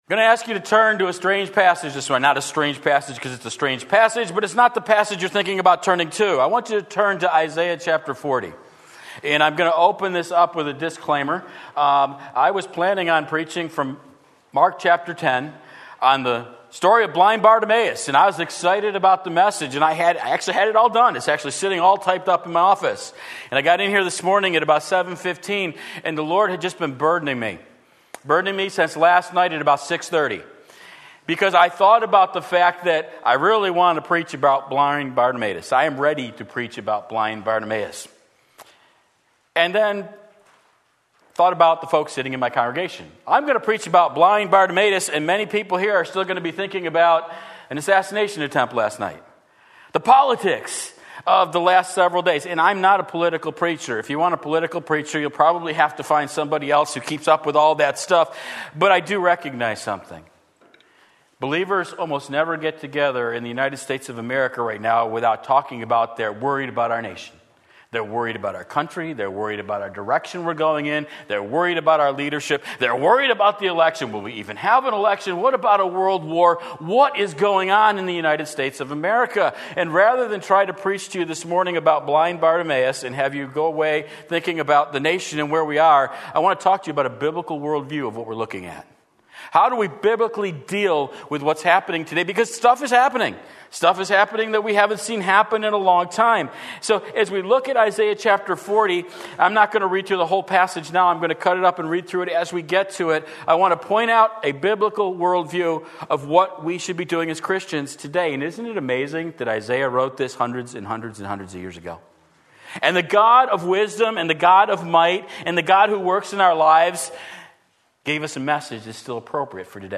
Sermon Link
In Times of Political Turmoil Isaiah 40 Sunday Morning Service